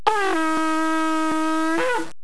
Tekiah
TEKIAH - the blast with honor guards before and after
tekiah.au